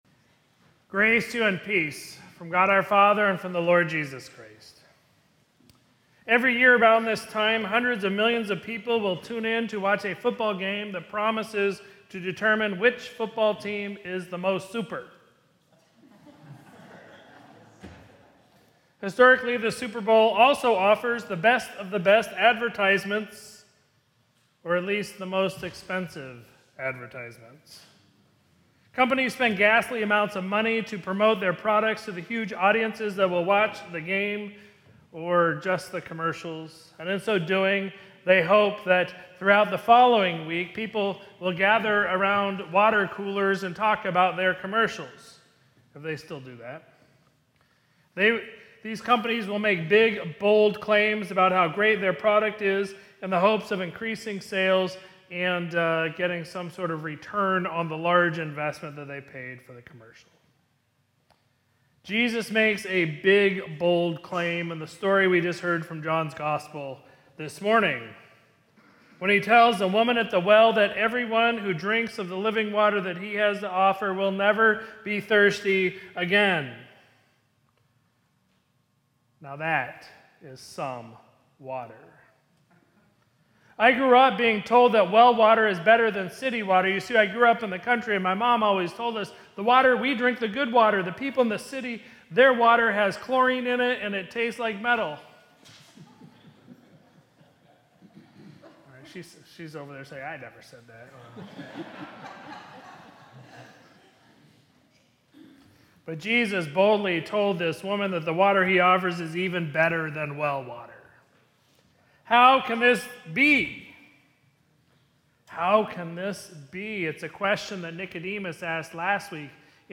Sermon from Sunday, February 1, 2026